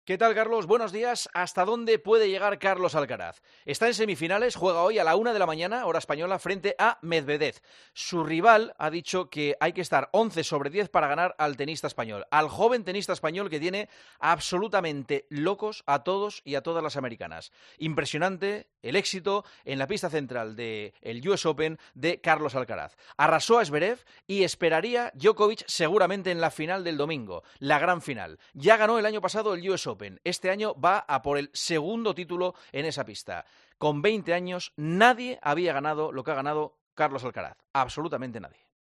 Escucha el comentario de Juanma Castaño en Herrera en COPE este viernes 8 de septiembre de 2023